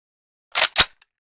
svt40_unjam.ogg